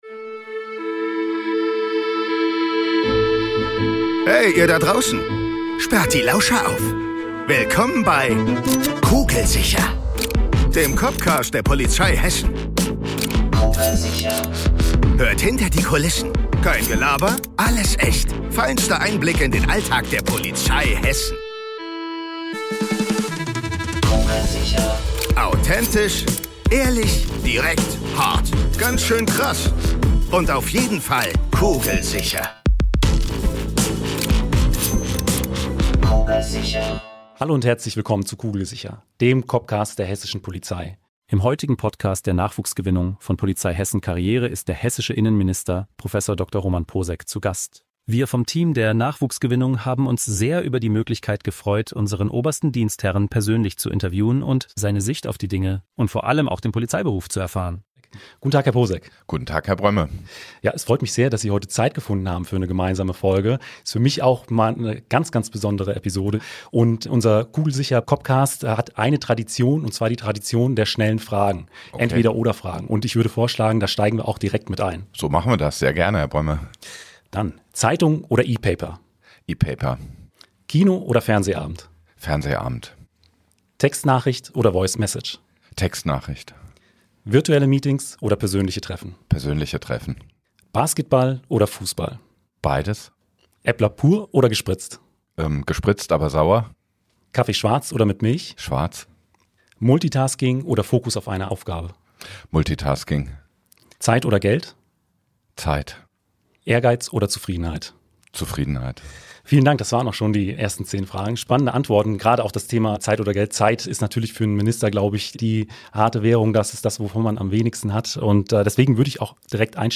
KEINE BEWEGUNG! Jetzt werden die Lauscher aufgesperrt! Bei uns dreht sich alles um den echten Polizeialltag, mit echten Cops am Mikro.